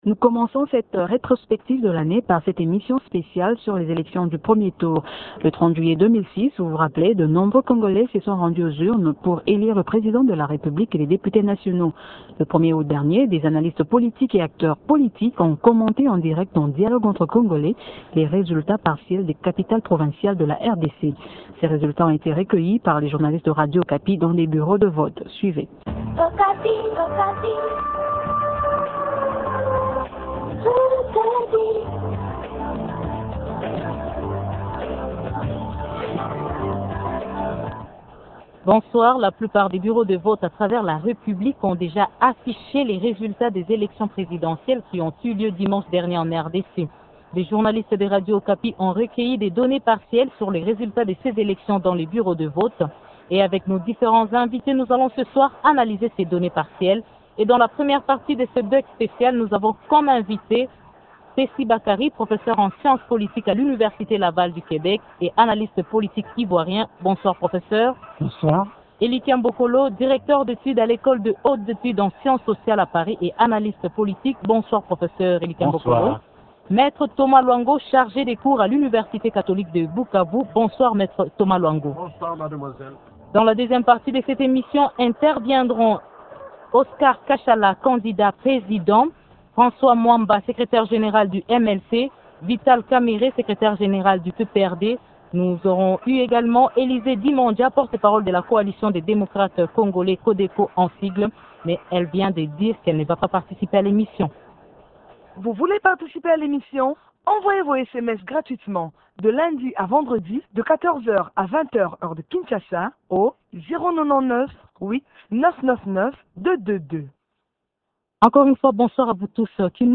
30 juillet 2006 : de nombreux congolais se sont rendus aux urnes pour élire le président de la république et les députés nationaux. Le 1er août 2006, des analystes politiques et acteurs politiques ont commenté en direct dans Dialogue entre Congolais les résultats partiels des capitales provinciales de la RDC.